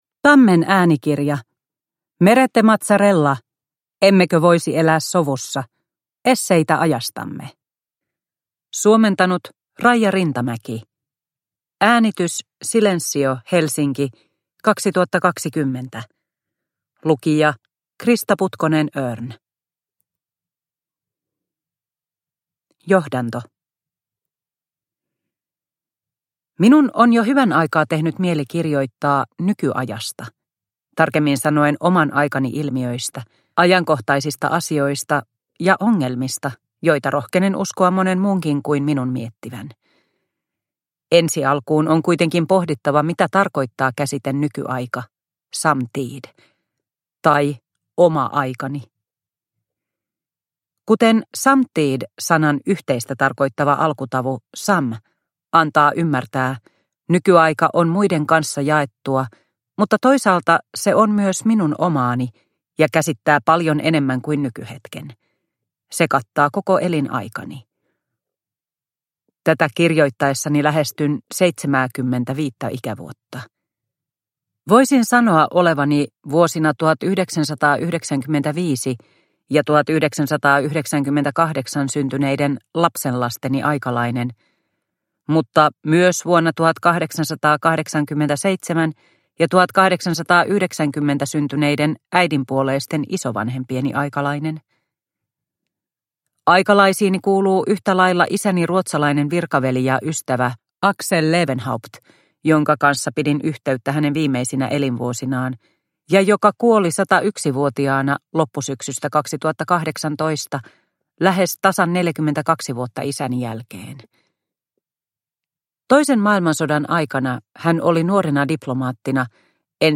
Emmekö voisi elää sovussa? - Esseitä ajastamme – Ljudbok – Laddas ner